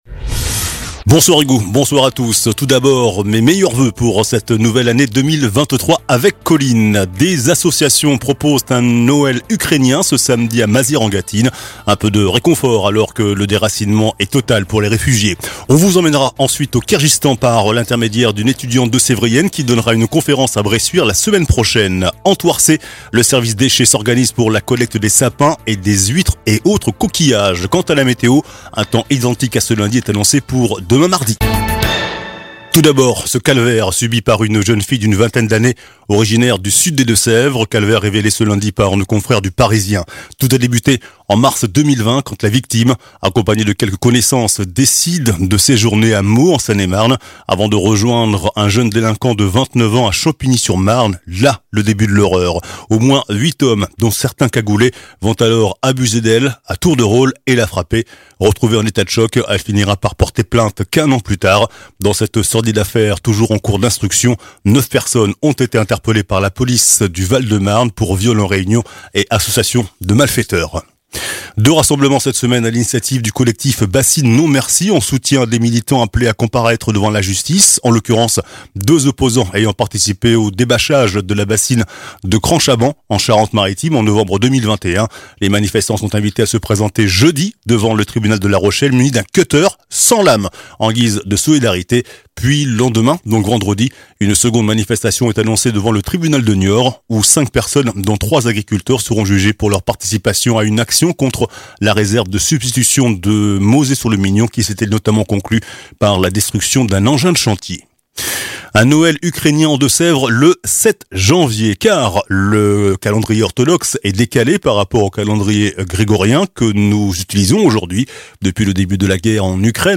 JOURNAL DU LUNDI 02 JANVIER ( SOIR )